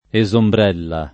[ e @ ombr $ lla ]